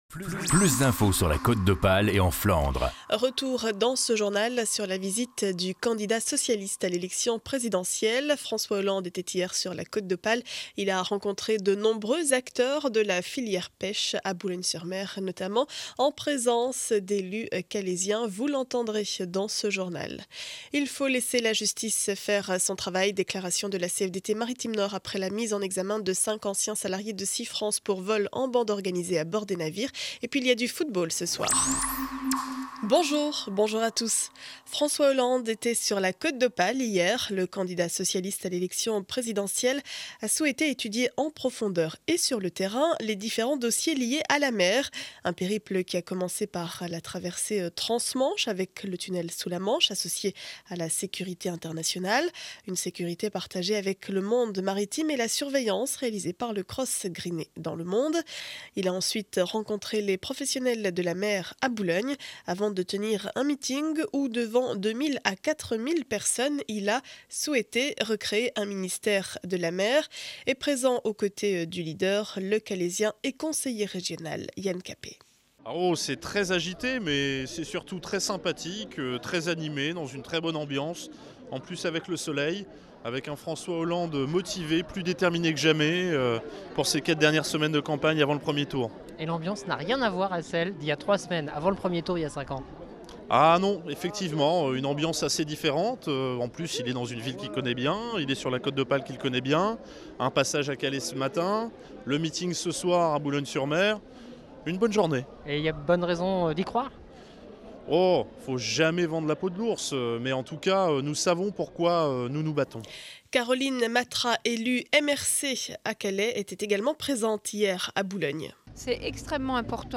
Journal du mercredi 28 mars 2012 7 heures 30 édition du Calaisis.